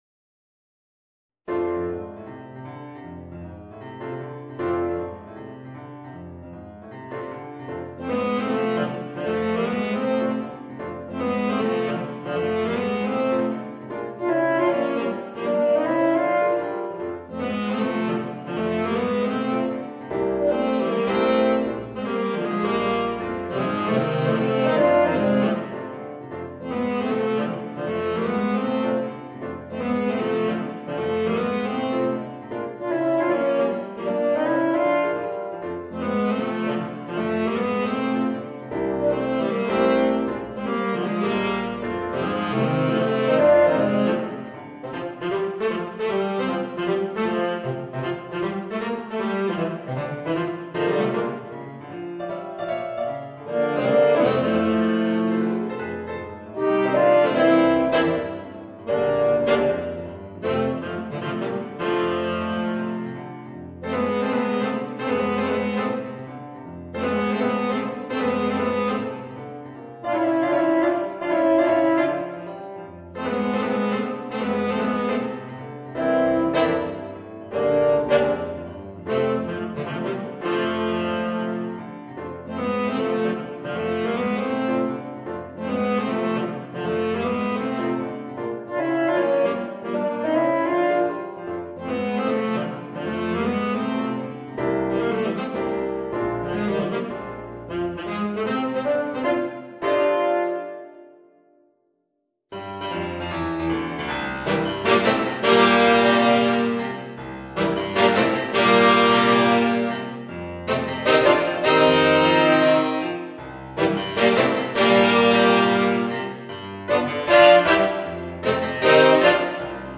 Voicing: 2 Tenor Saxophones and Piano